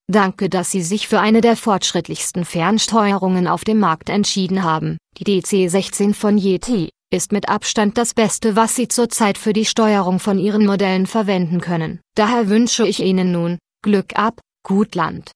Sound erstellen mit originaler Stimme
Im Anhang eine Kostprobe , so werde ich zur Zeit von meiner DC16 begrüsst!